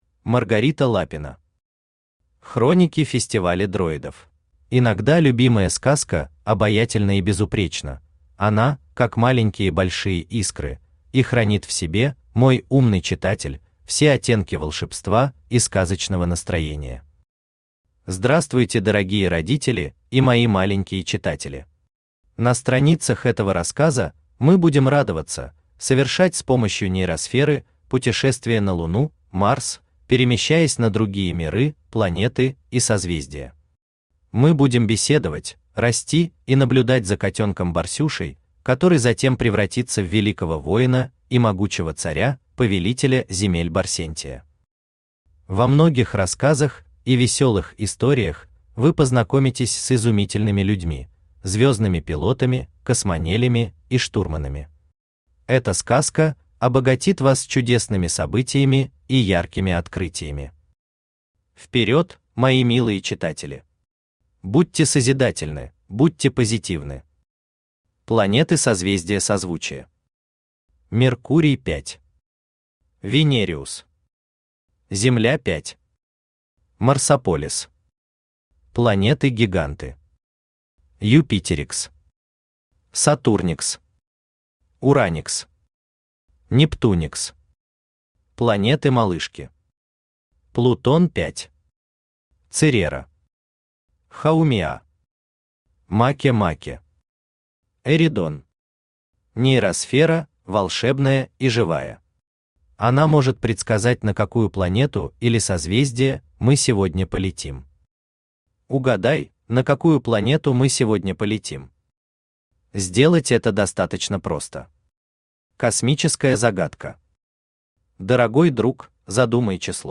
Аудиокнига Хроники фестиваля дроидов | Библиотека аудиокниг
Aудиокнига Хроники фестиваля дроидов Автор Маргарита Лапина Читает аудиокнигу Авточтец ЛитРес.